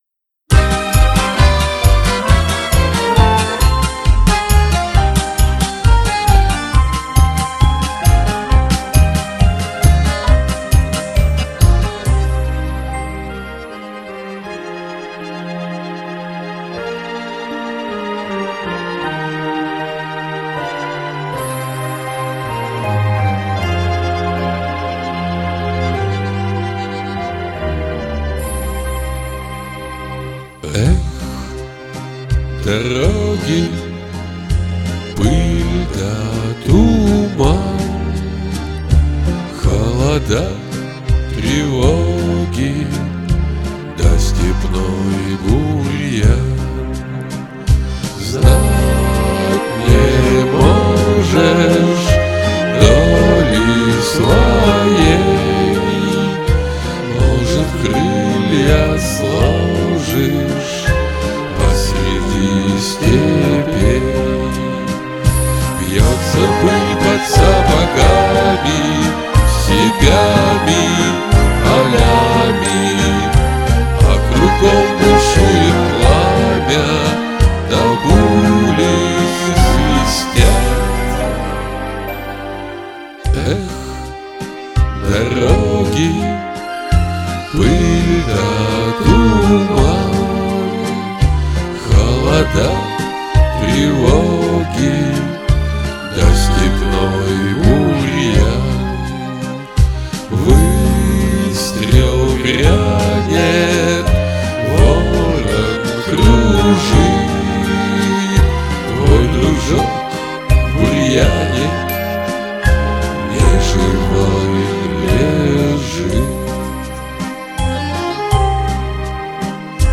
(попурри из советских военных песен)